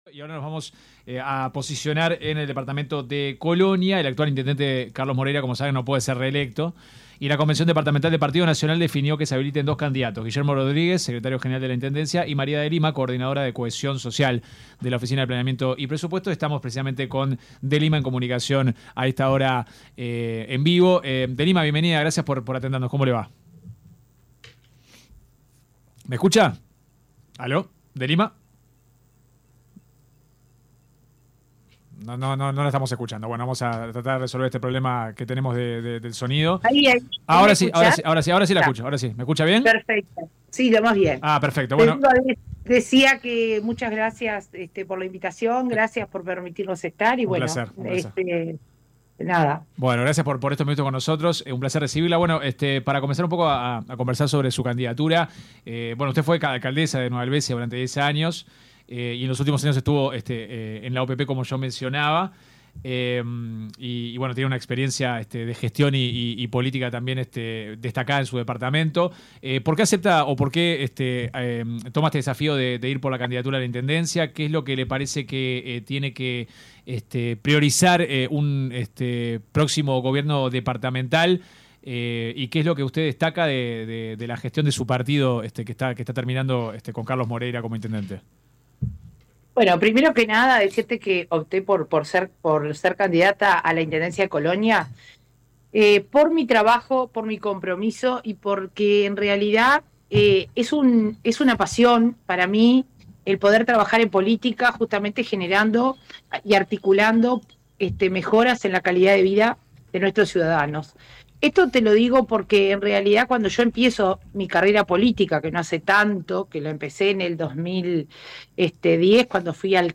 Escuche la entrevista completa La candidata a la Intendencia de Colonia